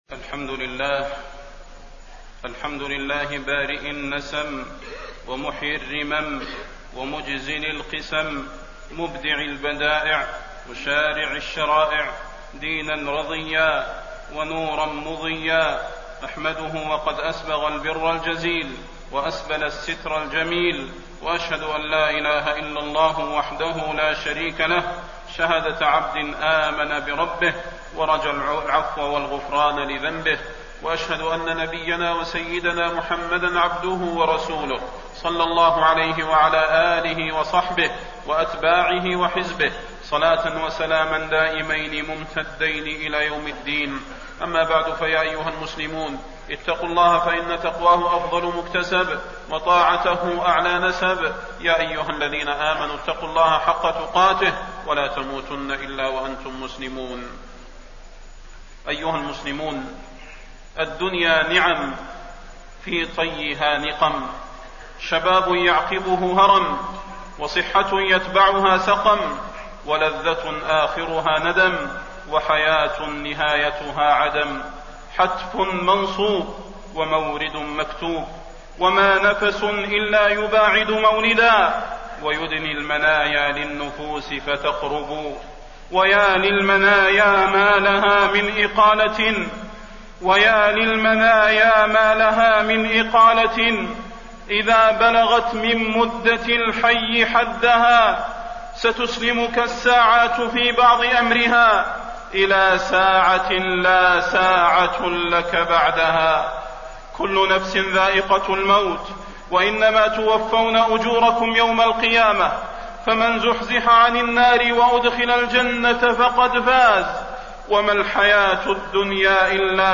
تاريخ النشر ٢٦ ذو الحجة ١٤٣٢ هـ المكان: المسجد النبوي الشيخ: فضيلة الشيخ د. صلاح بن محمد البدير فضيلة الشيخ د. صلاح بن محمد البدير اغتنام الأوقات بالأعمال الصالحات